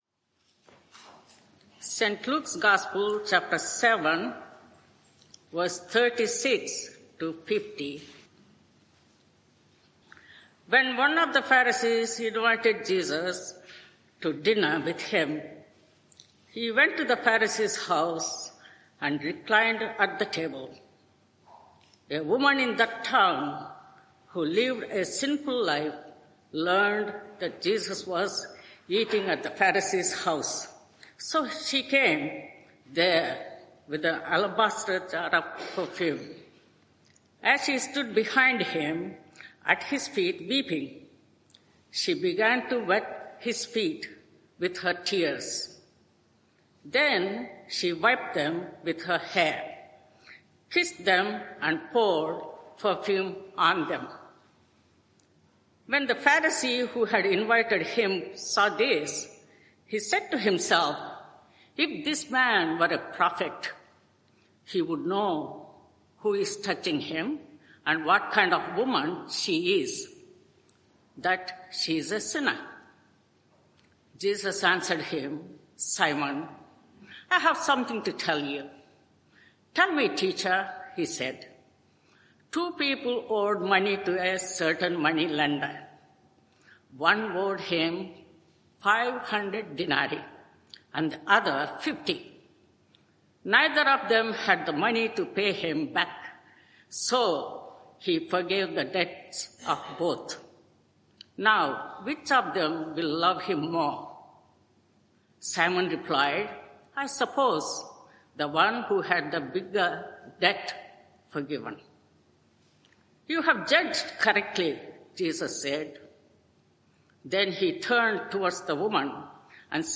Media for 11am Service on Sun 23rd Mar 2025 11:00 Speaker
What a Saviour! Theme: Luke 7:36-50 Sermon (audio) Search the media library There are recordings here going back several years.